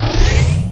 pickup_health.wav